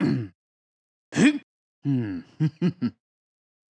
casting_success.wav